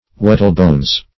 Meaning of whettlebones. whettlebones synonyms, pronunciation, spelling and more from Free Dictionary.